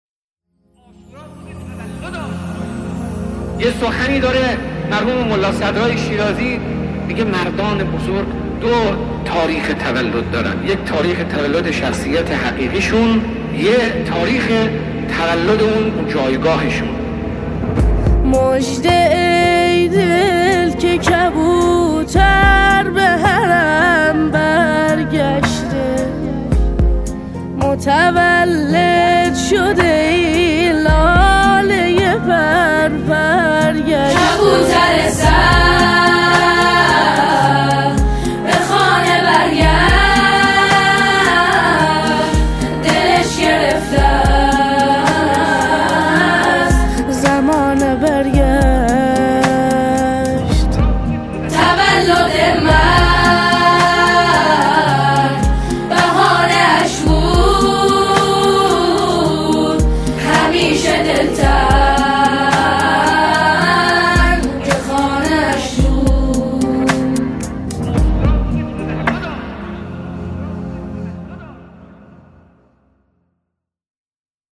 سرود شهید رئیسی